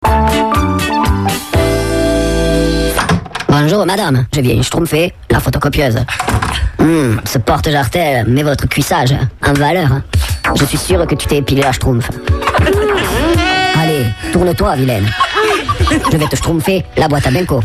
en harder